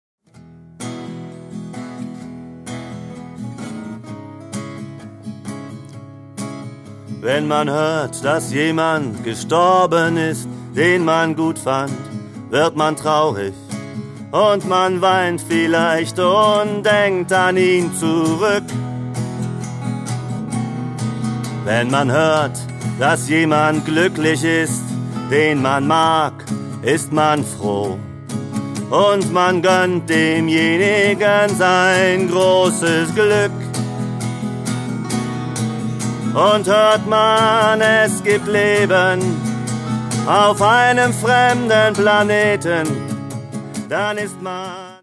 Ich mache deutschsprachige Lieder zur Gitarre.